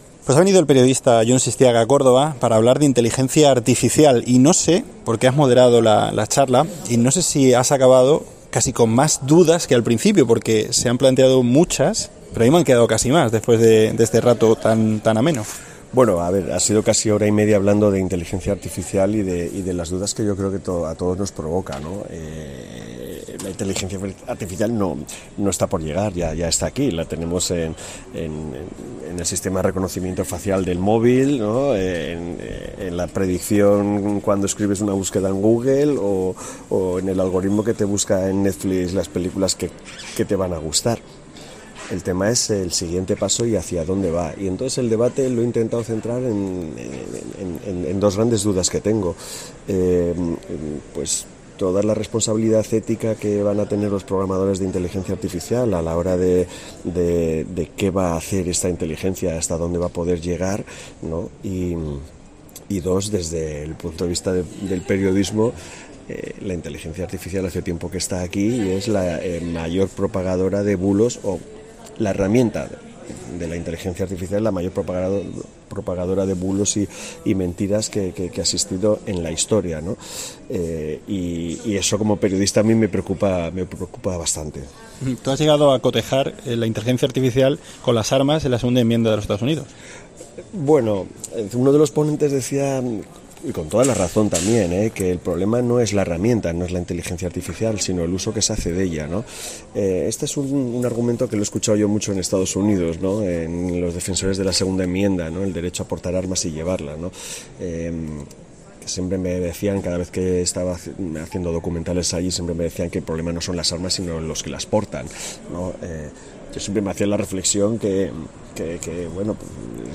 El coloquio estuvo moderado por el periodista Jon Sistiaga, quien atendió a COPE Córdoba.